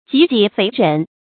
瘠己肥人 jí jǐ féi rén
瘠己肥人发音
成语注音 ㄐㄧˊ ㄐㄧˇ ㄈㄟˊ ㄖㄣˊ